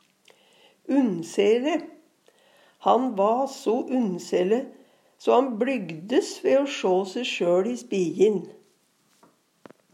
unnsele - Numedalsmål (en-US)